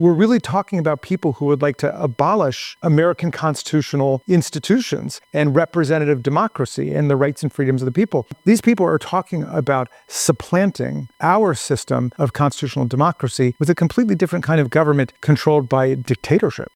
Raskin has been working on some of the more than 70 lawsuits against the President’s agenda. He told reporters that the type of people Trump has working in his government reorganization efforts are a real threat to the country’s form of government…